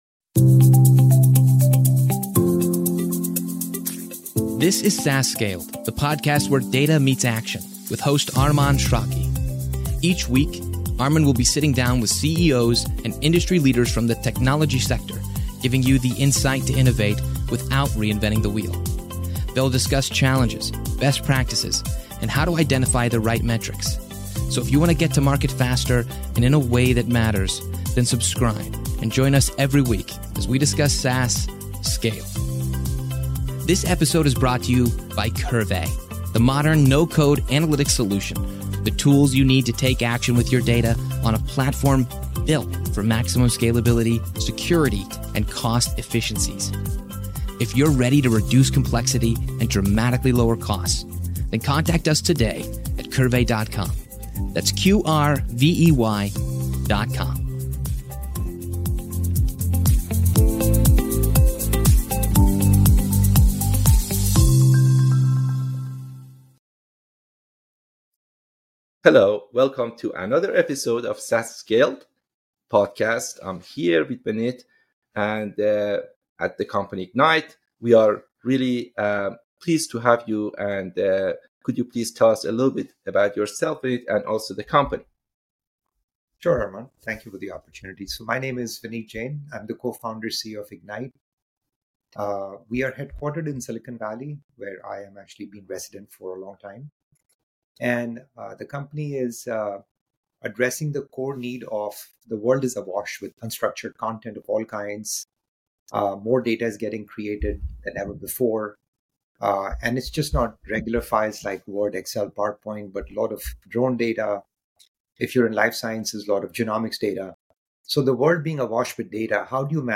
SaaS Scaled - Interviews about SaaS Startups, Analytics, & Operations